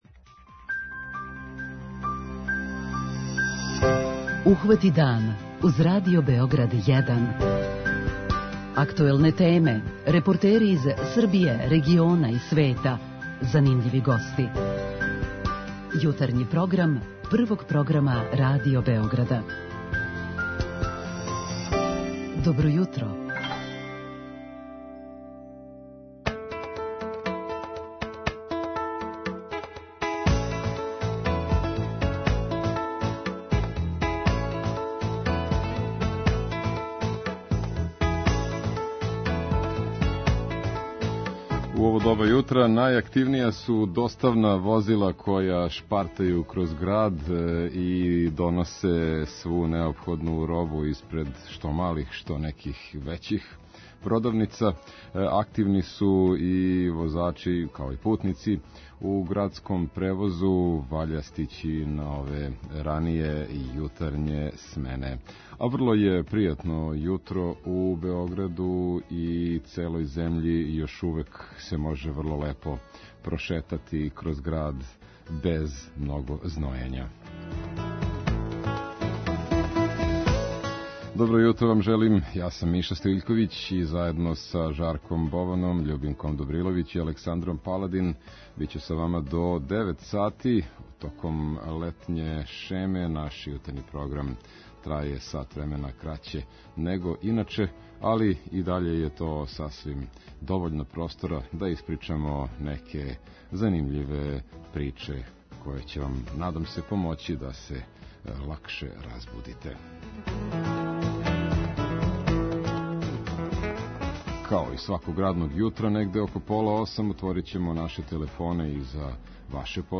Као и сваког дана, преносимо вам најсвежије вести из земље, региона и света.